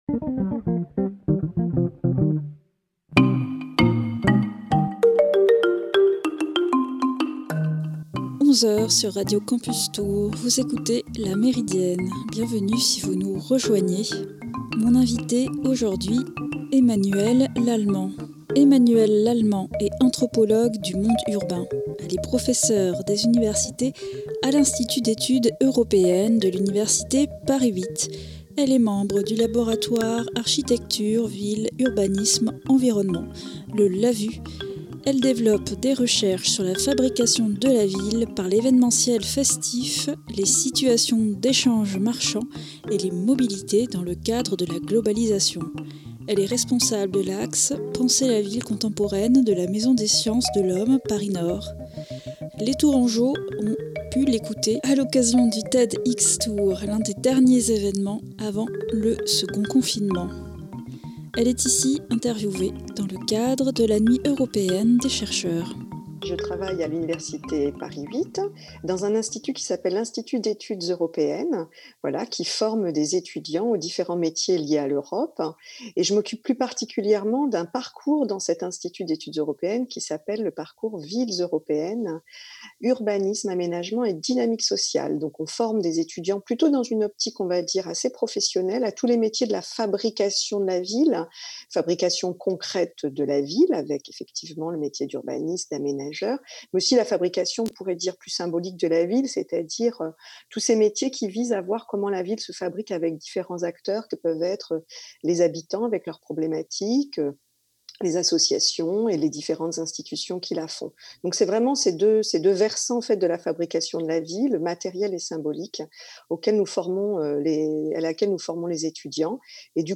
Elle est ici interviewée dans le cadre de la Nuit Européenne des Chercheurs.